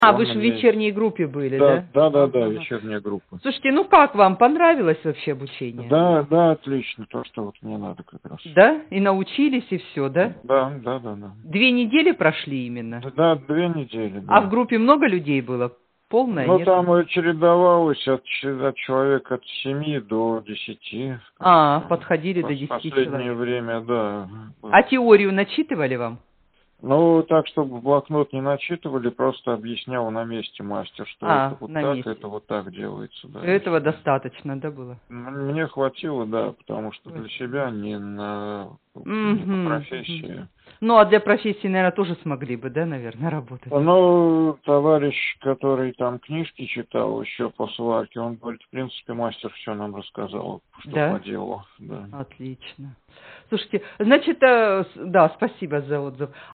Аудио Отзывы